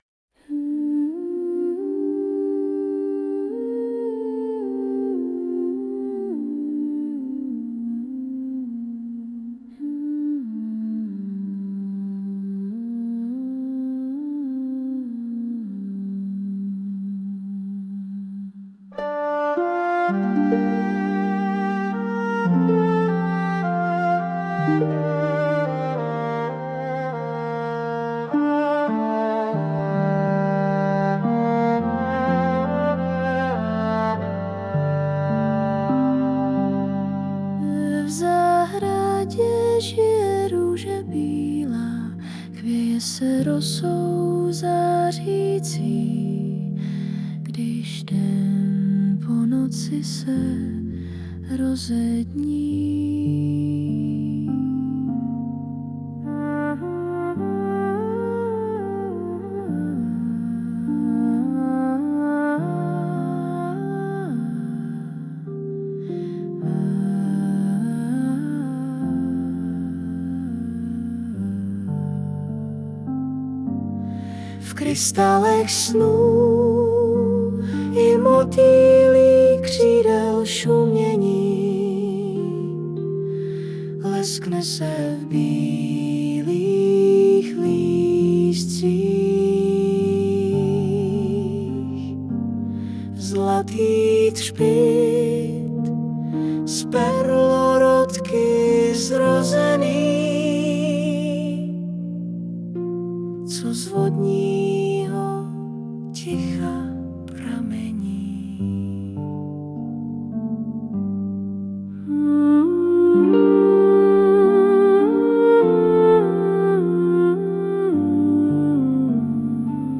2025 & Hudba, zpěv a obrázek: AI
Měla to být melodie plná jemných nuancí a tichých momentů, které by připomínaly její schopnost nalézat krásu v jednoduchosti a tichu.